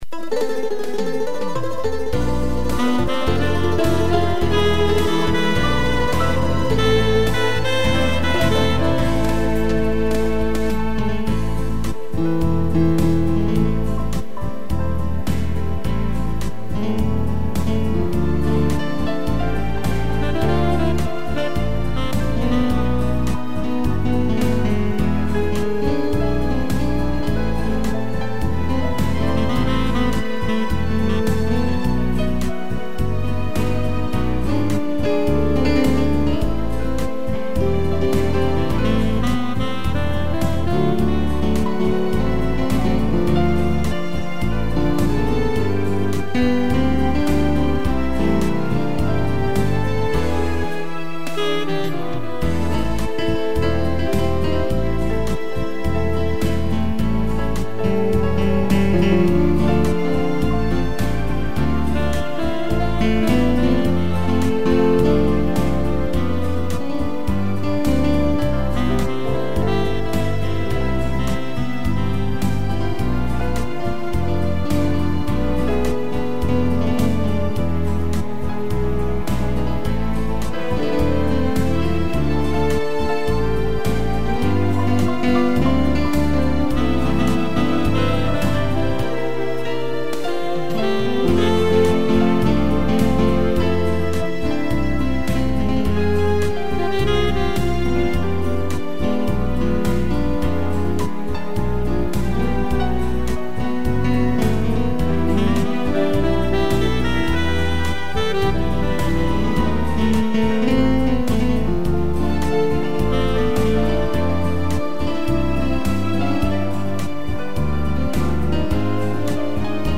piano, cello, violino e sax